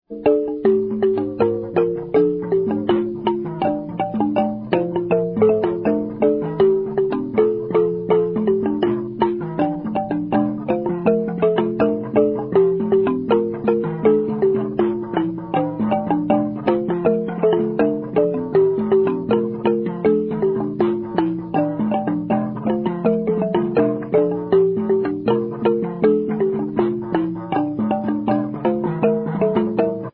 Khubvi -- Thohoyandou -- Transvaal (Limpopo) -- South Africa
Indigenous music
Traditional music
Xylophone
Instrumental
Trio performance
Trio performance of traditional Venda tune with 21 key xylophone accompaniment
Cassette tape